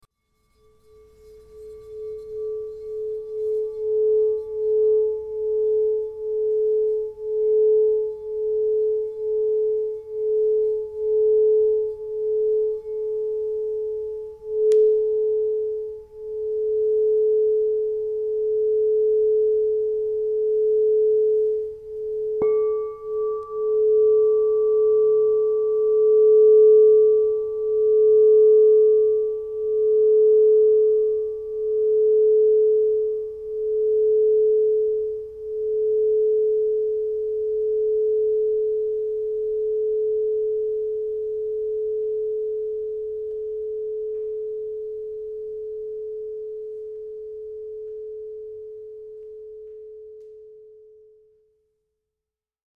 Introducing the epitome of elegance and harmony – our Platinum 7″ A Crystal Quartz Practitioner Bowl, a rare gem infused with the precious essence of real